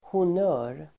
Ladda ner uttalet
Uttal: [hon'ö:r]